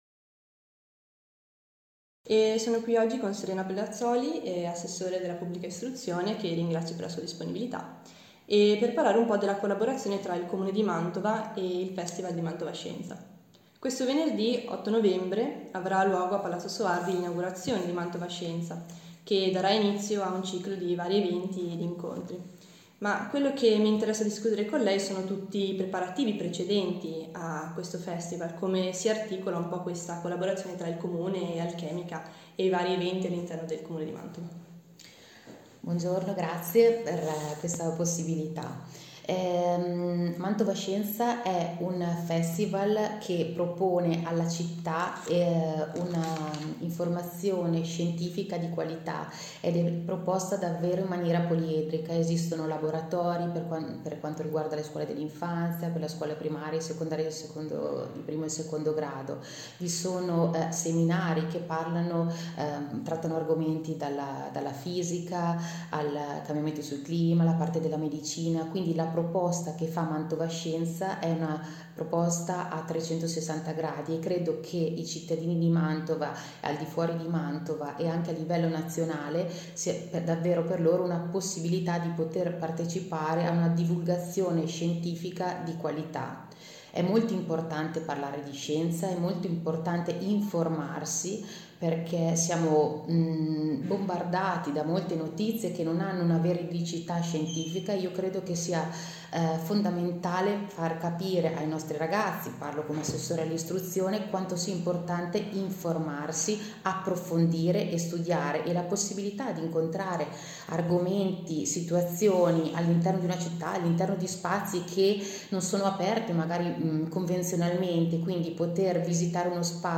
Intervista all’assessore all’istruzione Serena Pedrazzoli
00-Mantovascienza-intervista-a-Serena-Pedrazzoli.mp3